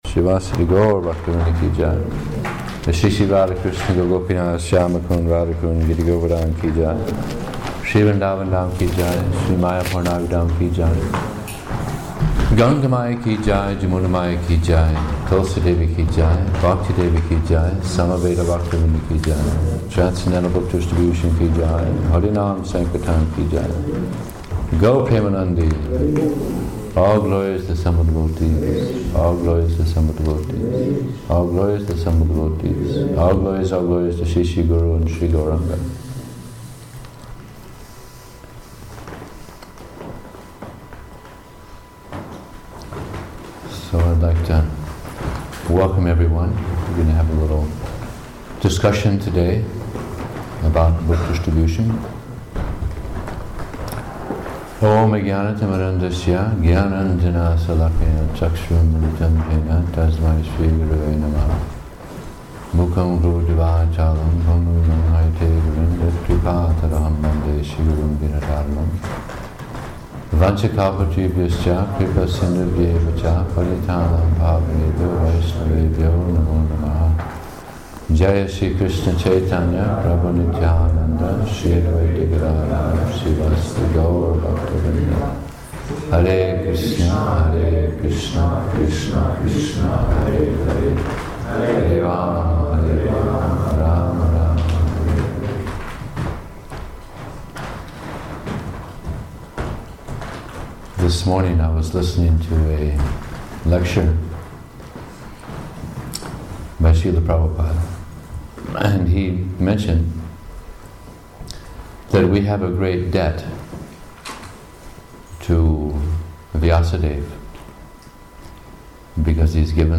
Posted on May 3, 2013 in Book Distribution Audio Lectures